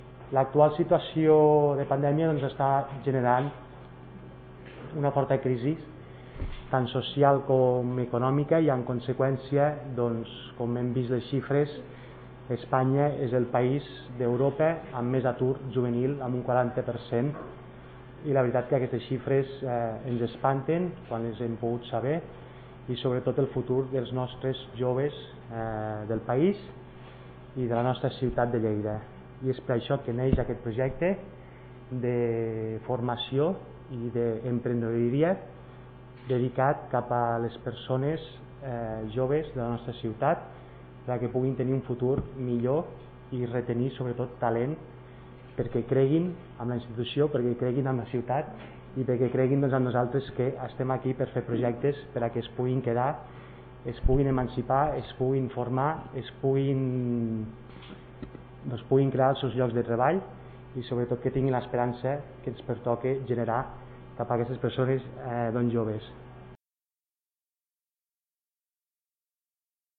arxiu-de-so-del-regidor-ignasi-amor-sobre-el-projecte-fem-coop